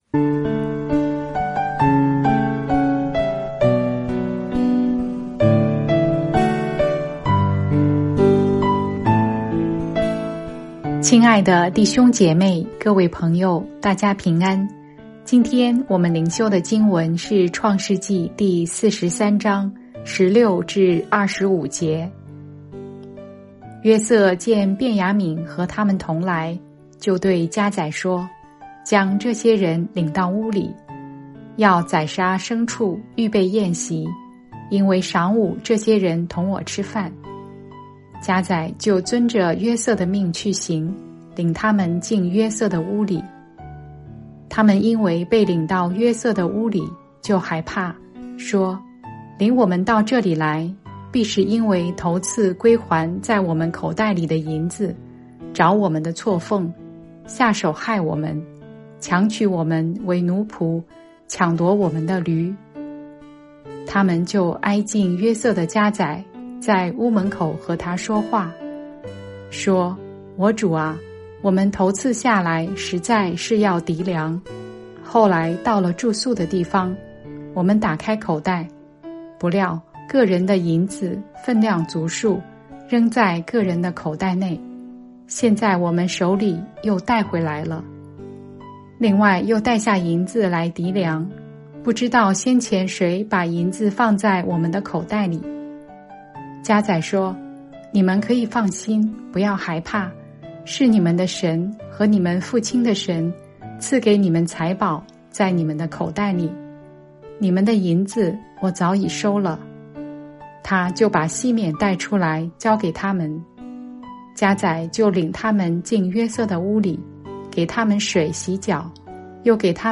每天閱讀一段經文，聆聽牧者的靈修分享，您自己也思考和默想，神藉著今天的經文對我說什麼，並且用禱告來回應當天的經文和信息。